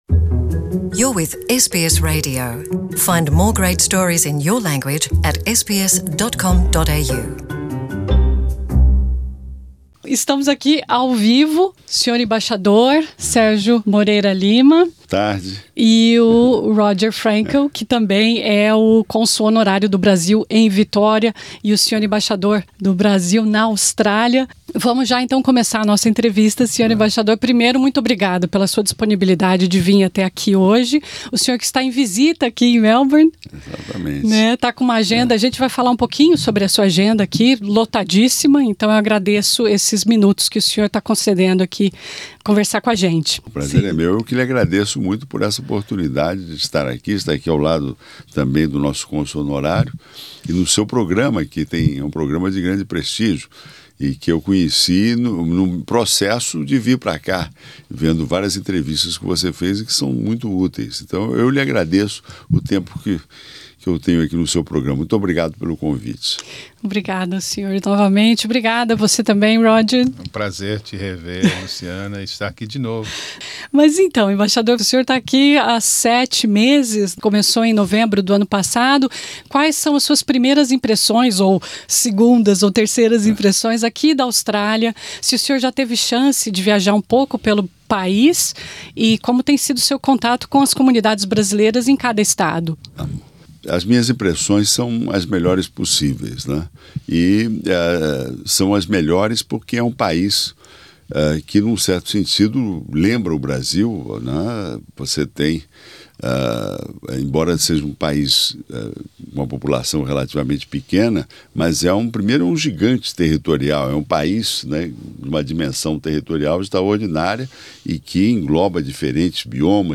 Sérgio Eduardo Moreira Lima e Roger Frankel, cônsul honorário de Melbourne, durante entrevista na SBS Source: LF/SBSPortuguese